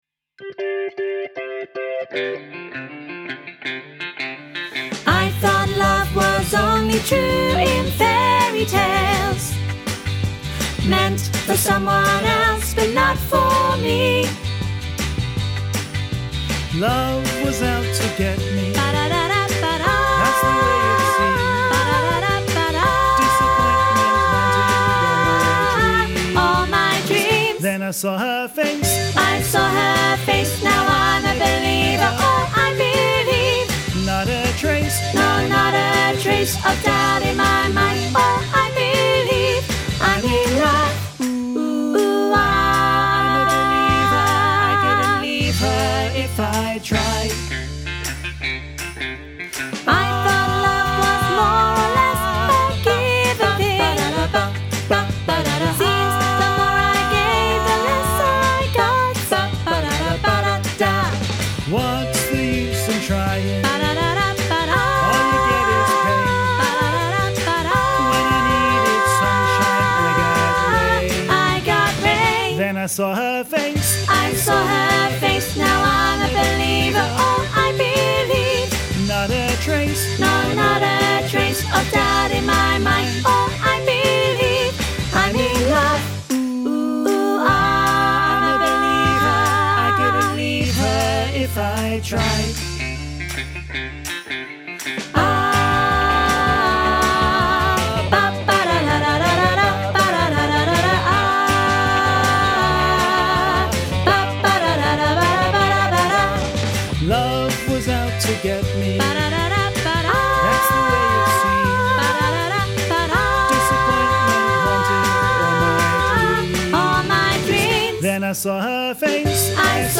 Training Tracks for I'm a Believer
im-a-believer-soprano-half-mix.mp3